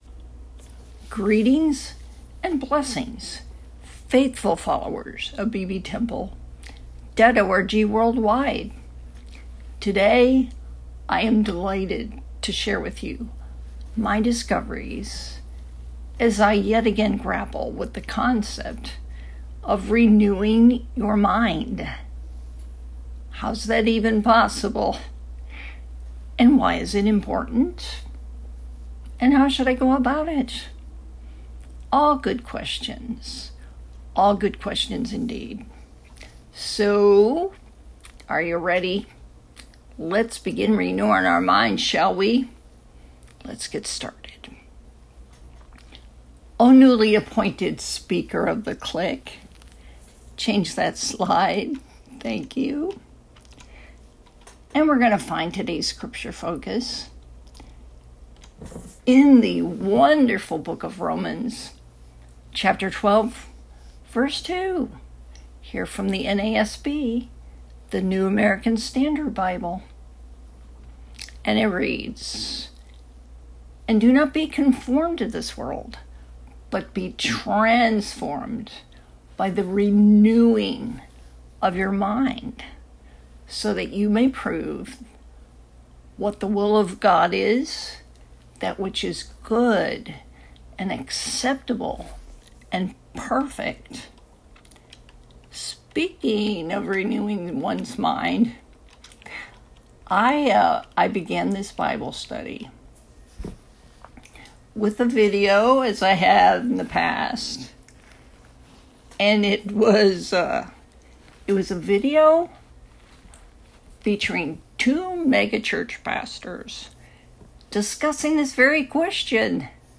Sunday School
“Renewing Your Mind” – Bartimaeus Baptist Temple